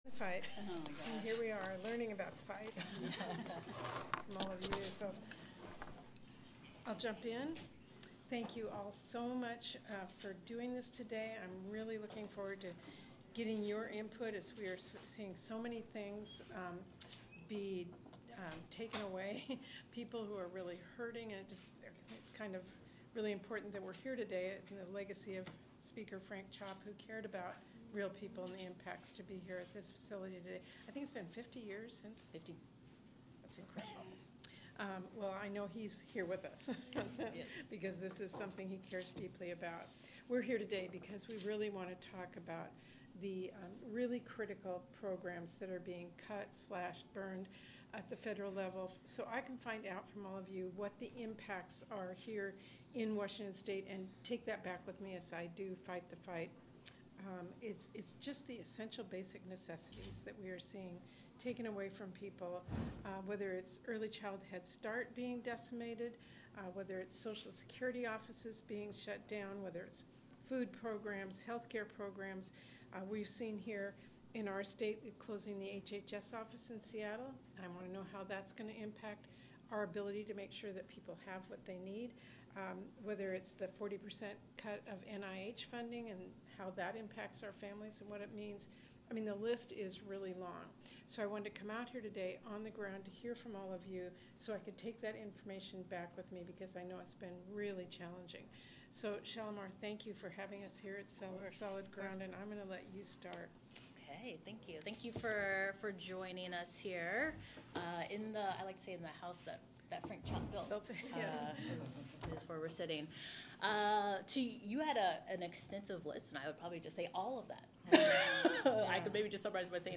Trump-cuts-to-health-care-child-care-Seattle-roundtable.m4a